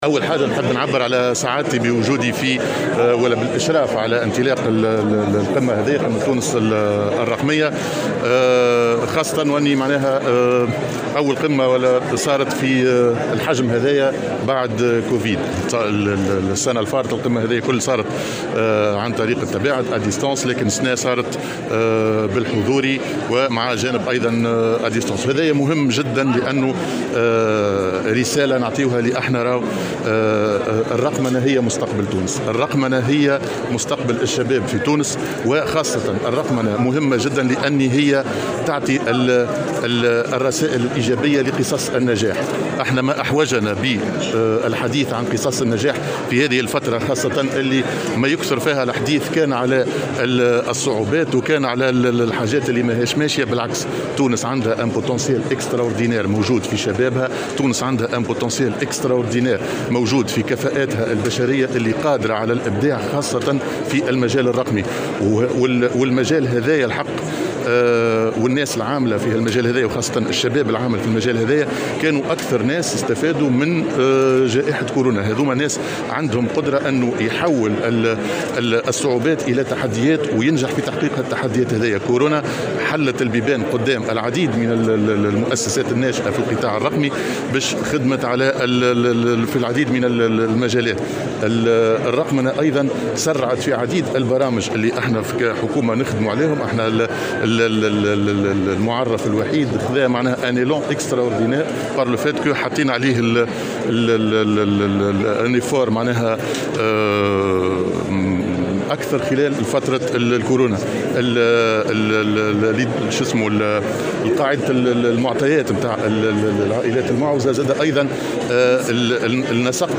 قال رئيس الحكومة هشام المشيشي في تصريح لمراسل الجوهرة أف أم على هامش مشاركته في افتتاح قمة تونس الرقمية، إن زيارة قطر ناجحة جدا تأكد خلالها بعد لقاء أمير قطر أن تونس لازالت تحظى بدعم أصدقائها.